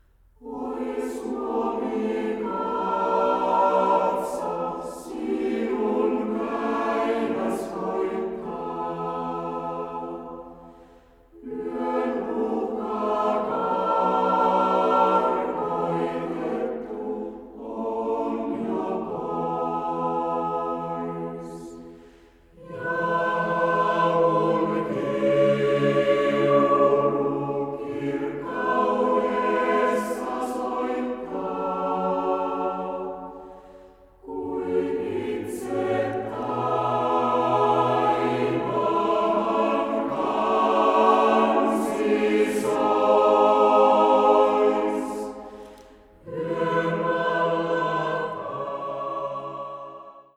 mezzosopraano
sopraano
tenori